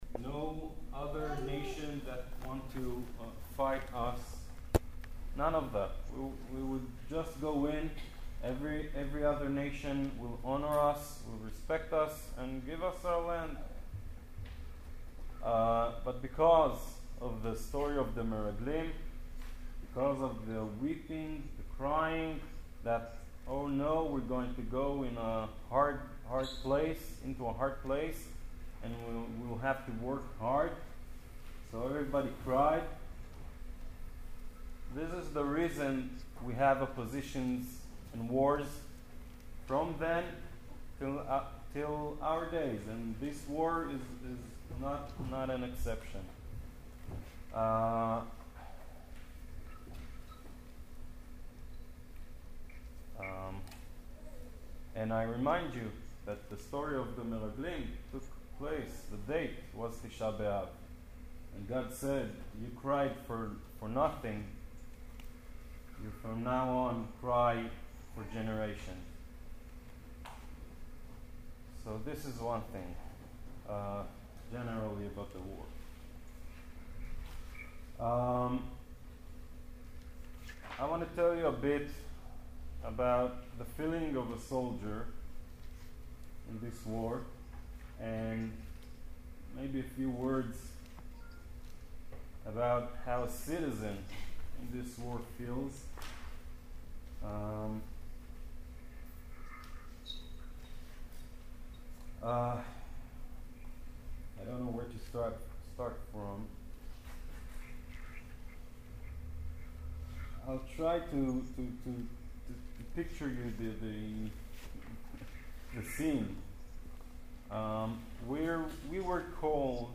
On Tisha B’av JLIC at Brooklyn College and JLIC of Greater Toronto joined together for a very meaningful night.